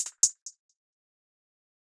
Closed Hats
Hihat Roll 2.wav